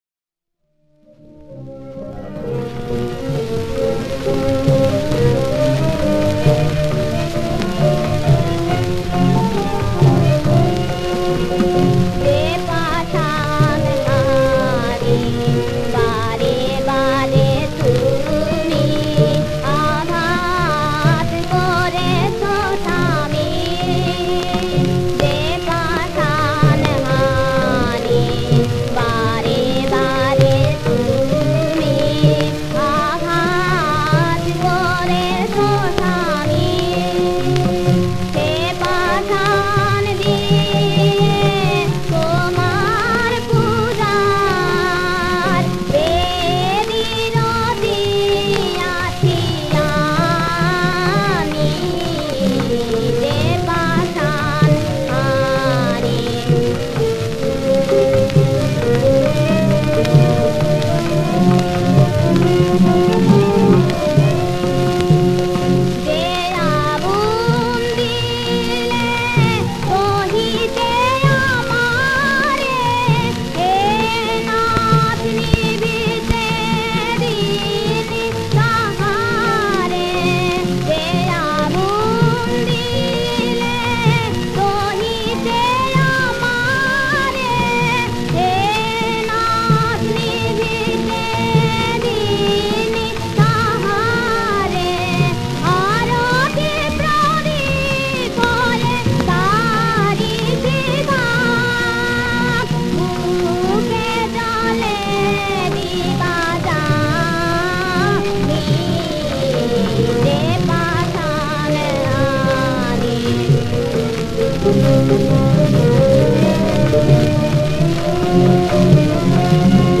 • বিষয়াঙ্গ: ভক্তি
• সুরাঙ্গ: স্বকীয় বৈশিষ্ট্য
• তাল: কাহারবা
• গ্রহস্বর: গপা